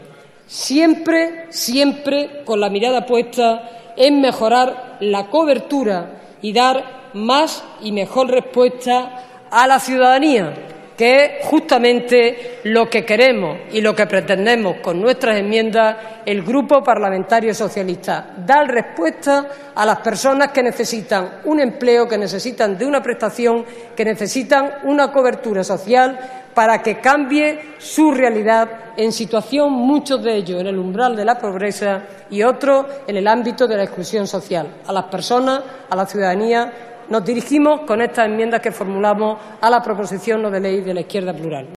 Fragmento de la intervención de Rosa Aguilar en el debate de la proposición no de ley de IU, ICV–EUiA, CHA: La Izquierda Plural, para regular una prestación económica de renta mínima garantizada de ciudadanía. 16/09/14